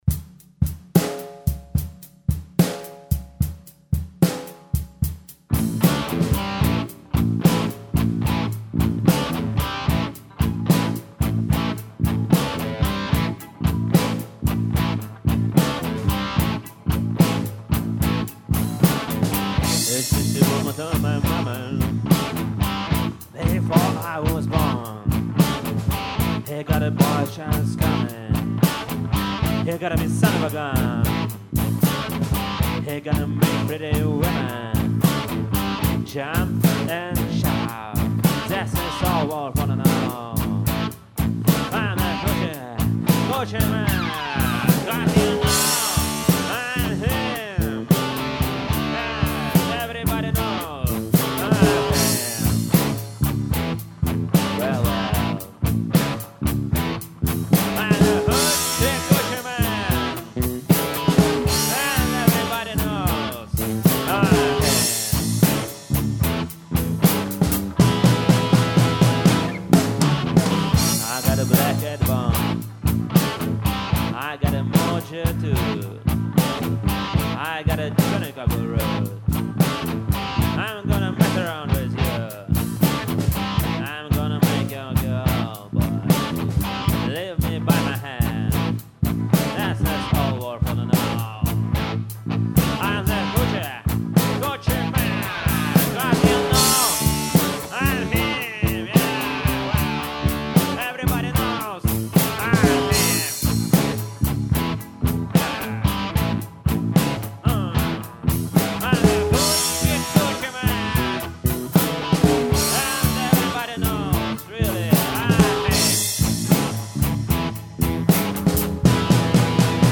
Попытки хорошо сиграть и записать... (26 Августа 2009 и 02 Сентября 2009)...